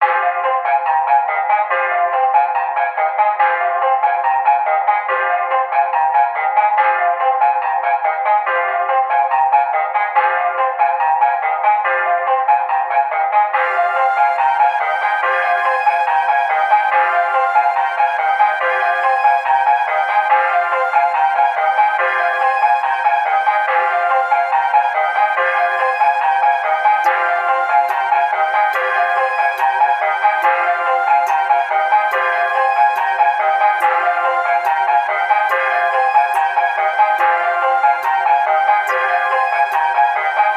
SOUTHSIDE_melody_loop_radio_142_Fm.wav